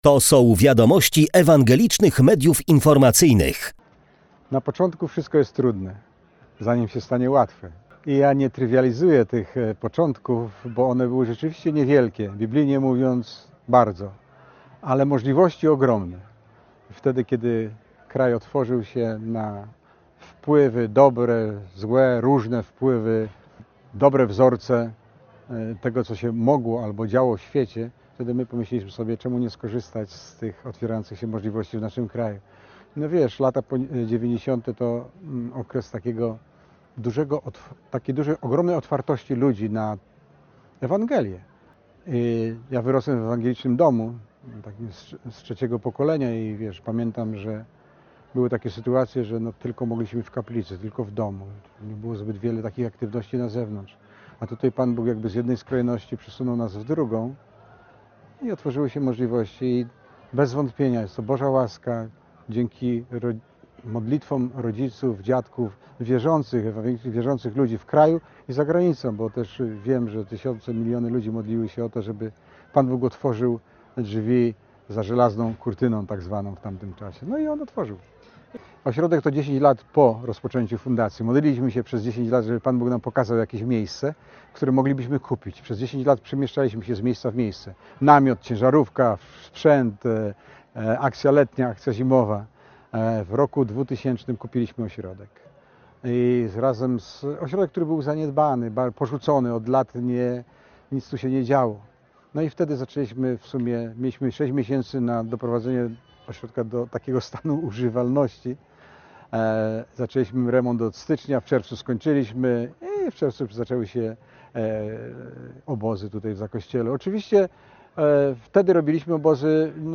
Podczas dziękczynnego nabożeństwa wspominano początki działania Fundacji.